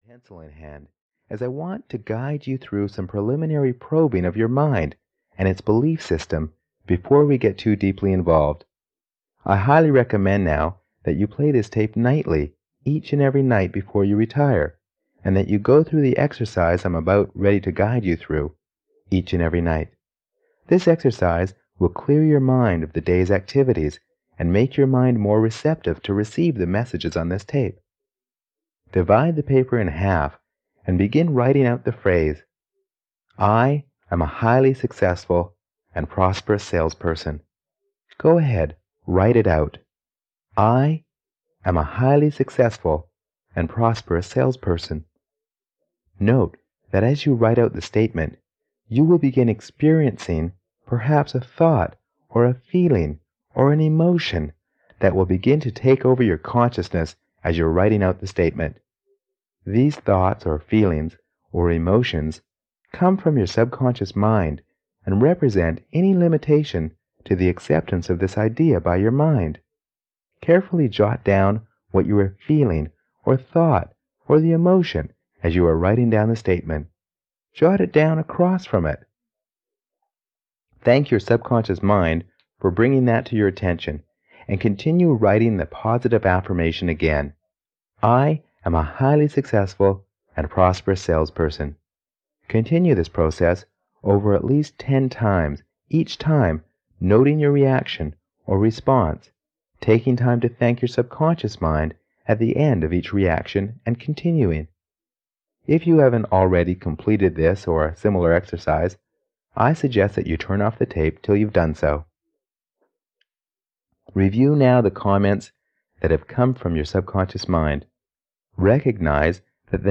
Celý popis Rok vydání 2020 Audio kniha Ukázka z knihy 150 Kč Koupit Ihned k poslechu – MP3 ke stažení Potřebujete pomoct s výběrem?